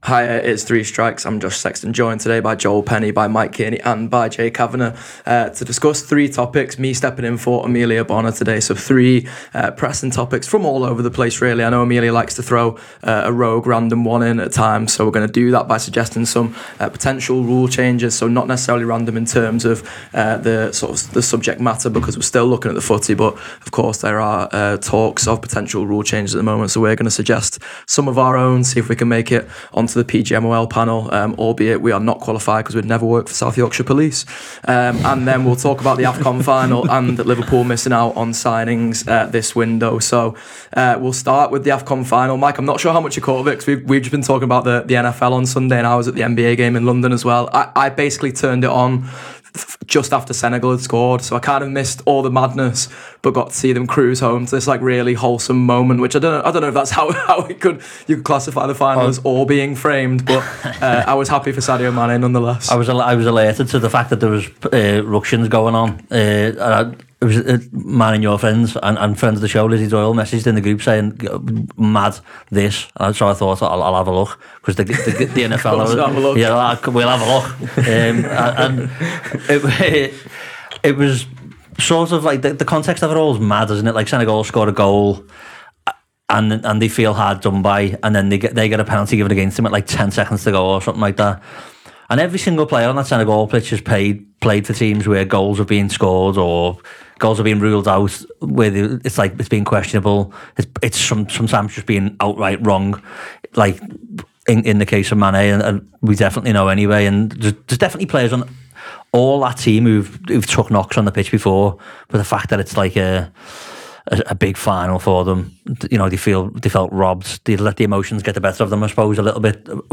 Getting stuck into three big talking points from the football world and beyond, this week, the subjects of the discussion are whether Liverpool are wasting the January transfer window, the Africa Cup Of Nations and potential rule changes for referees.